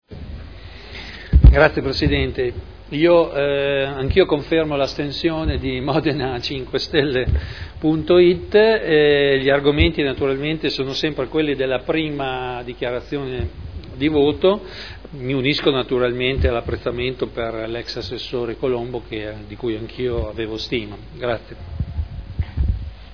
Seduta del 07/05/2012. Dichiarazione di voto.